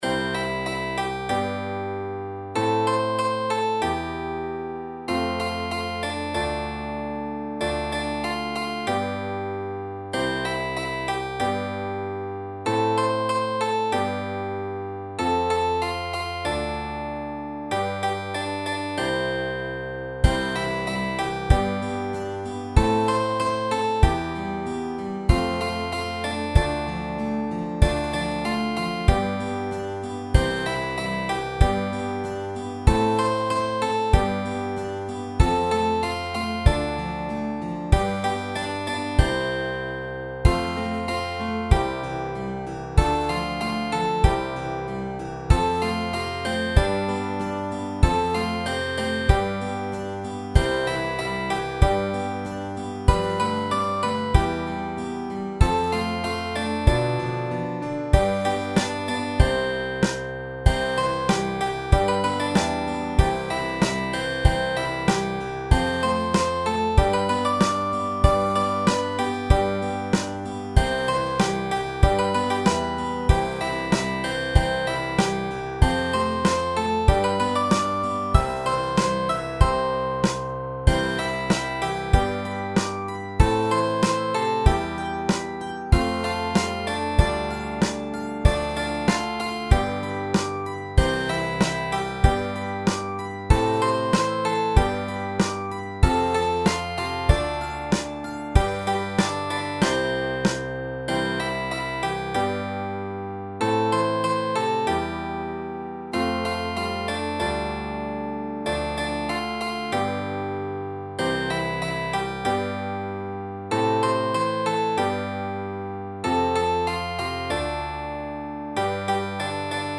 インストゥルメンタル明るい穏やか